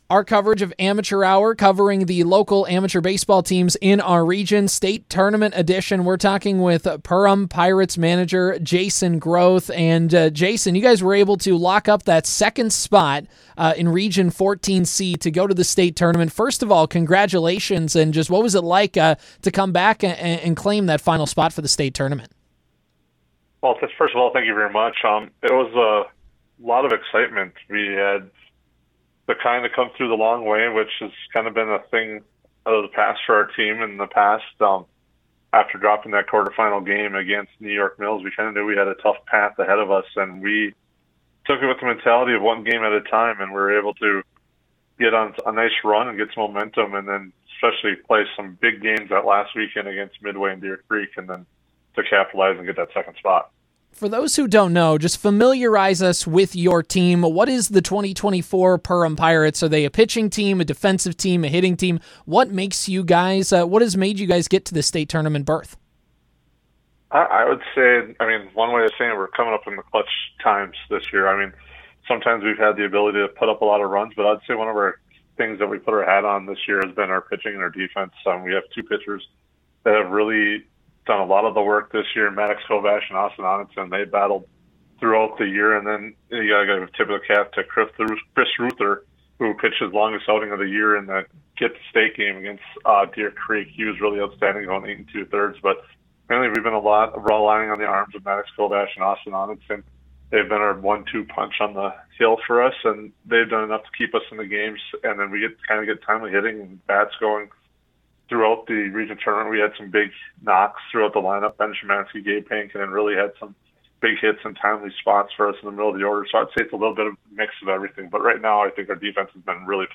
Interview
PERHAM-PIRATES-INTERVIEW-8-15-24.mp3